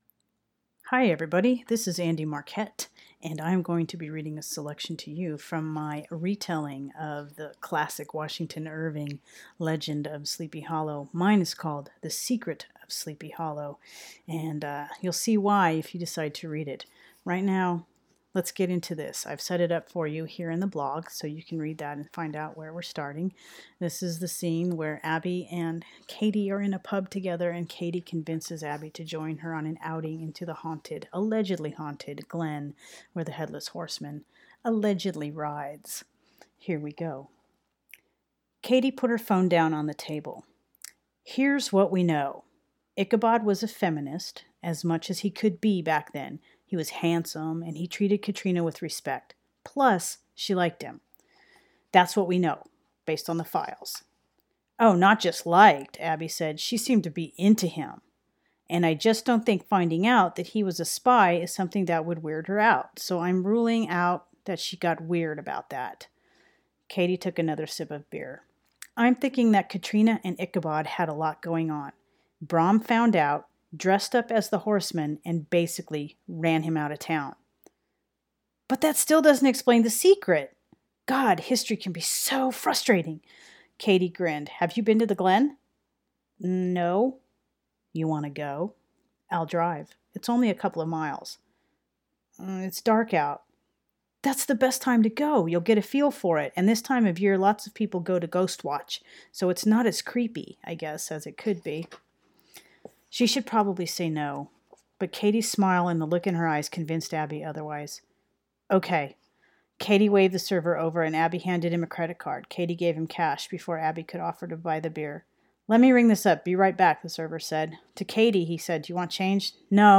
Here’s a Halloween reading for you!
secret-of-sleepy-hollow-reading.mp3